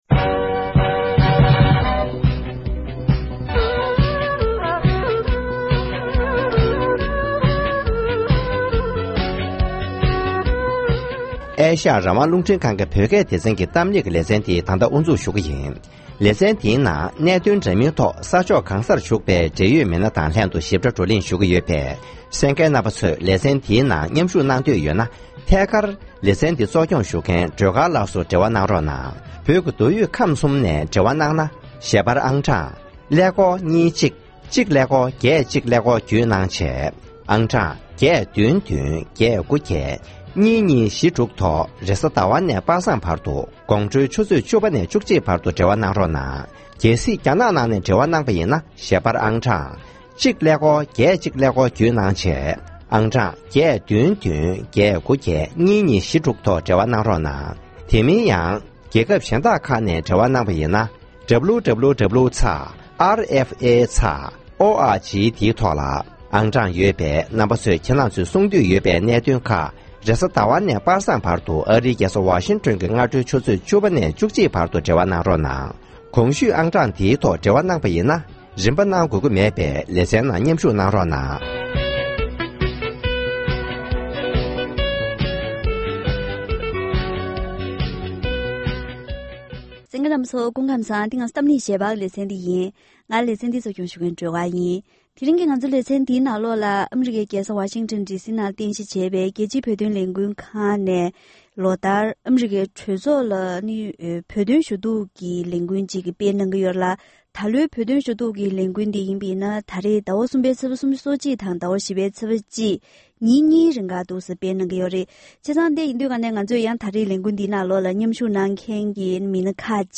༄༅༎དེ་རིང་གི་གཏམ་གླེང་ལེ་ཚན་ནང་ཨ་རིའི་གྲོས་ཚོགས་སུ་བོད་དོན་ཞུ་གཏུགས་གི་ལས་འགུལ་སྤེལ་བའི་ཁྲོད་ནས་ས་གནས་ཁག་ནས་ཆེད་བཅར་ཞུས་པའི་མི་སྣ་ཁག་ཅིག་ལྷན་བོད་དོན་ཞུ་གཏུག་གི་གལ་འགངས་རང་བཞིན་དང་གནད་དོན་གང་གི་ཐོག་ཨ་རིའི་གྲོས་ཚོགས་ཀྱི་སྐུ་ཚབ་ལ་འགྲེལ་བརྗོད་གནང་བ། ཁོང་རྣམ་པའི་ཉམས་མྱོང་སོགས་ཀྱི་ཐོག་བཀའ་མོལ་ཞུས་པ་ཞིག་གསན་རོགས་གནང་།།